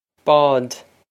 Bád Bawd
This is an approximate phonetic pronunciation of the phrase.